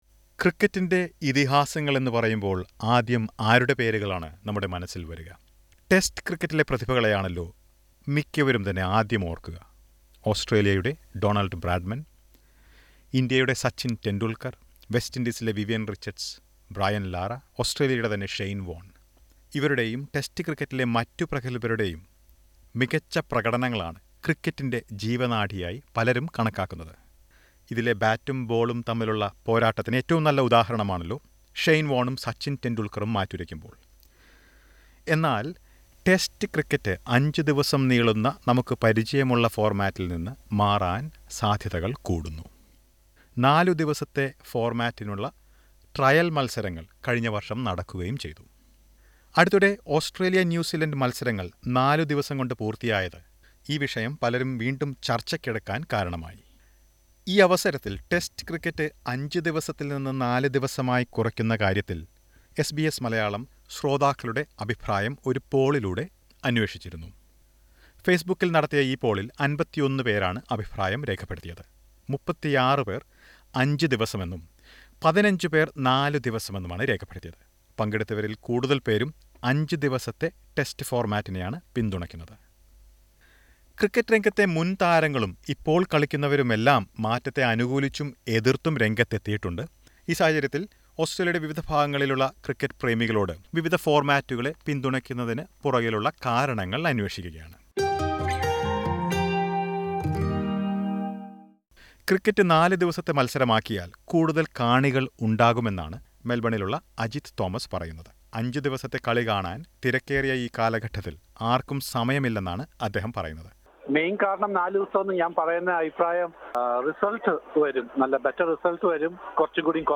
ടെസ്റ്റ് ക്രിക്കറ്റ് അഞ്ചു ദിവസത്തിൽ നിന്ന് നാല് ദിവസമാക്കി കുറക്കുന്ന കാര്യം ICC പരിഗണിക്കുകയാണ്. ഈ അവസരത്തിൽ അഞ്ച് ദിവസത്തെയും നാല് ദിവസത്തെയും ഫോർമാറ്റുകളെ പിന്തുണക്കുന്ന ചില ക്രിക്കറ്റ് പ്രേമികളുമായി എസ് ബി എസ് മലയാളം സംസാരിച്ചു.